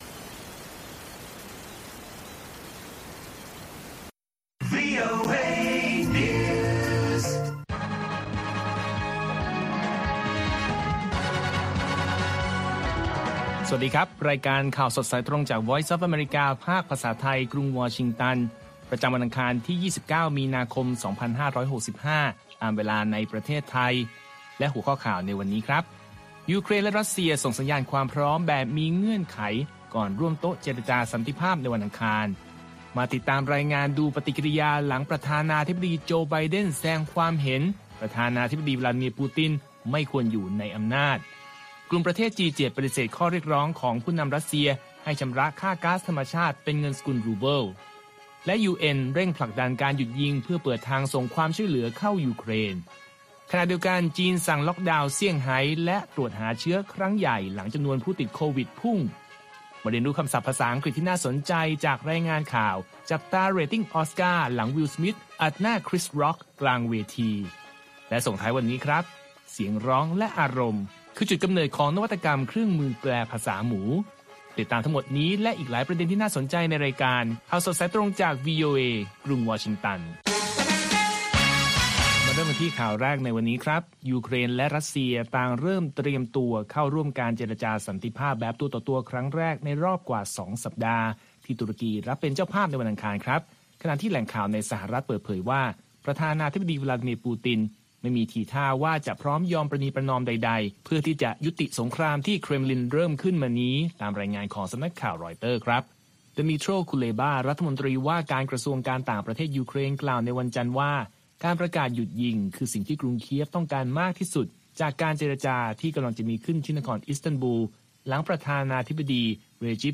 ข่าวสดสายตรงจากวีโอเอ ภาคภาษาไทย ประจำวันอังคารที่ 29 มีนาคม 2565 ตามเวลาประเทศไทย